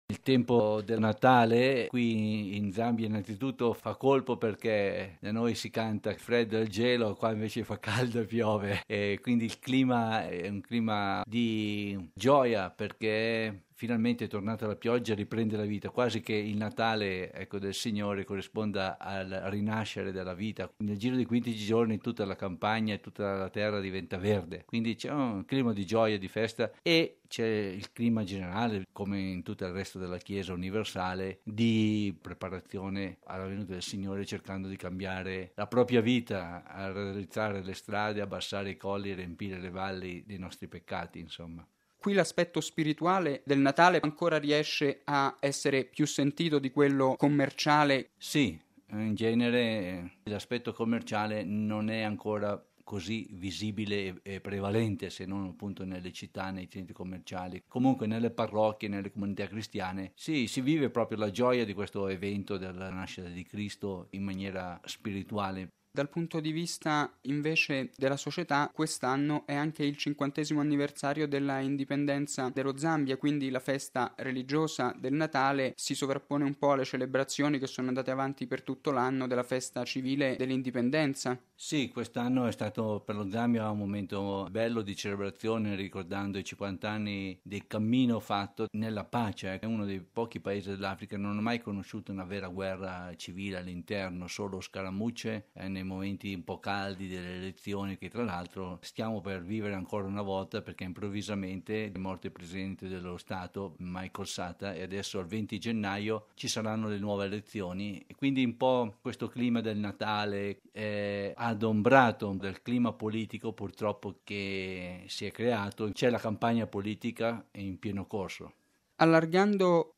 dalla capitale Lusaka